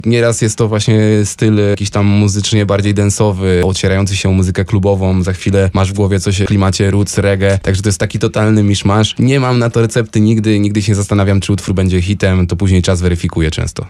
Gwiazdy polskiej sceny muzycznej, Jacek Stachursky i Paweł Lipski, czyli Nowator są już w Stanach Zjednoczonych i odwiedzili studio Radia Deon.